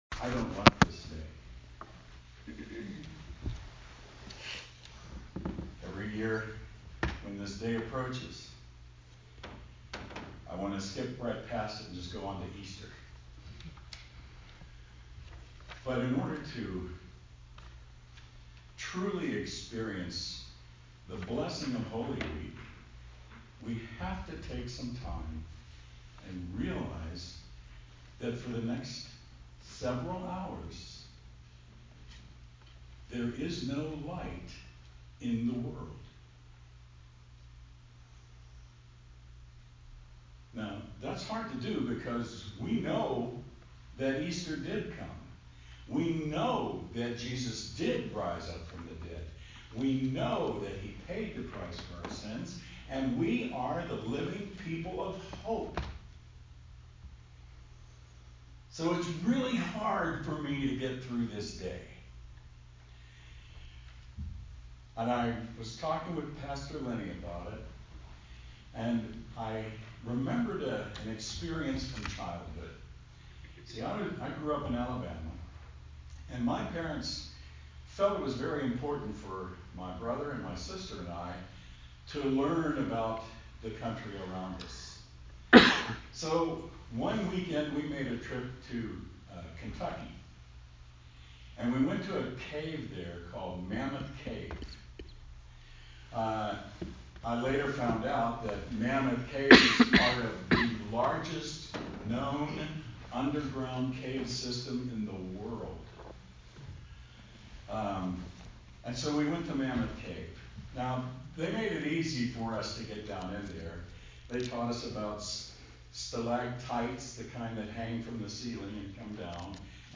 Sermon – Good Friday